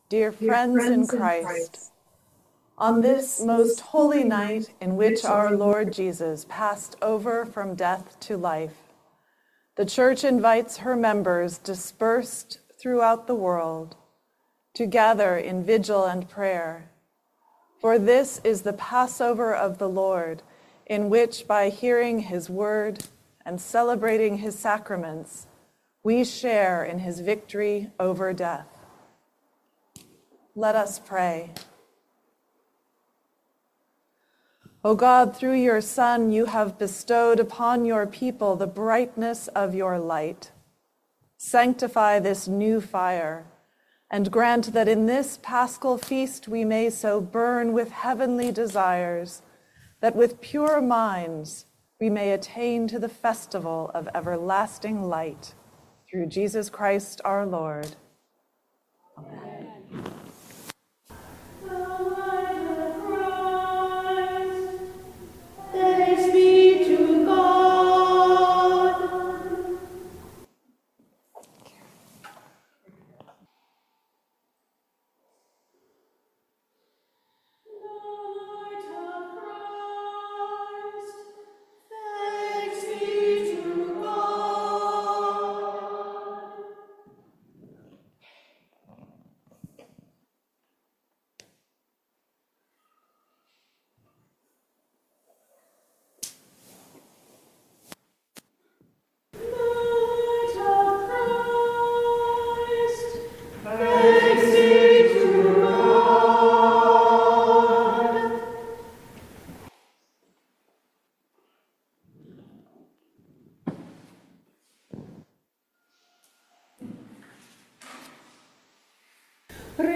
Audio recording of the 8pm hybrid/streamed service